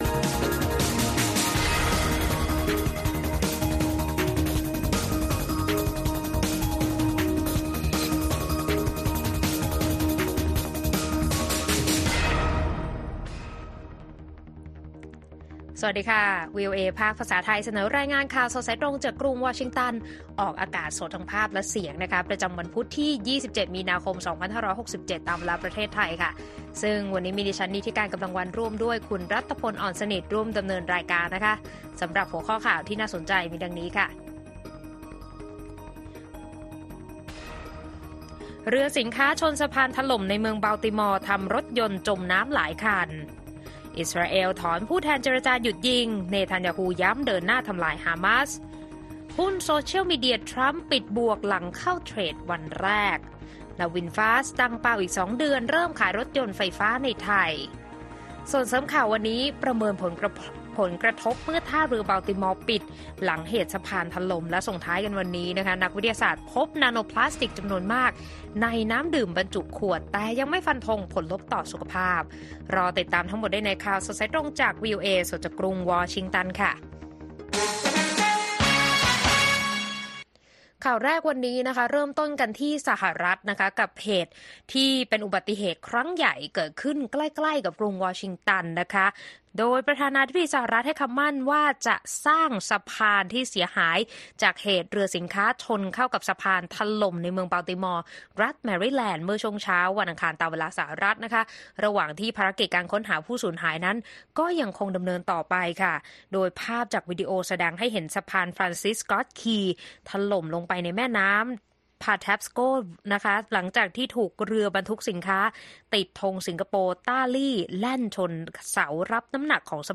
ข่าวสดสายตรงจากวีโอเอ ภาคภาษาไทย 6:30 – 7:00 น. วันพุธที่ 27 มีนาคม 2567